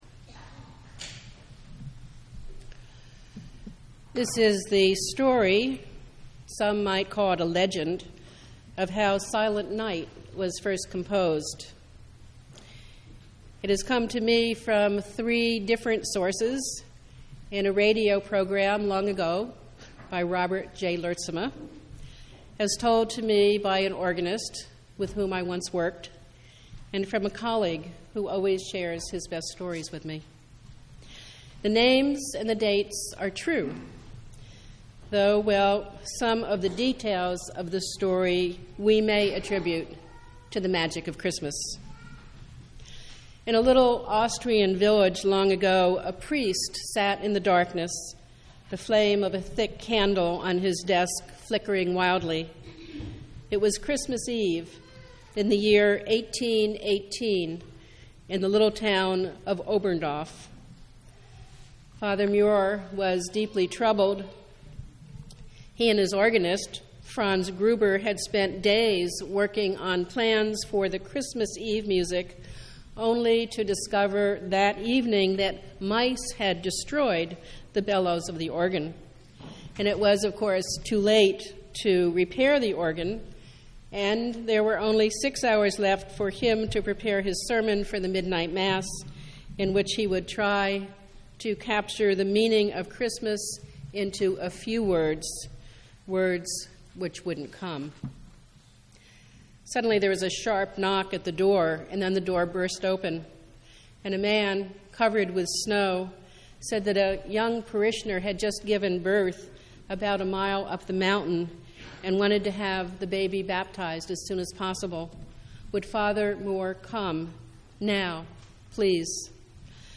Christmas Eve Service
Our Christmas Eve Candlelight Service of readings and music starts at 5:00 p.m. on December 24th.